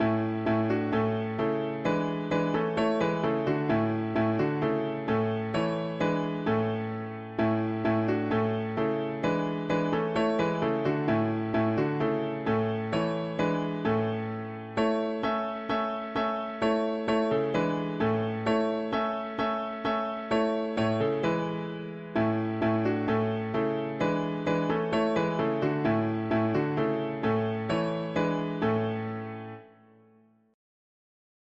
All is vain unless the … english christian 4part
Key: A major Meter: 87.87 D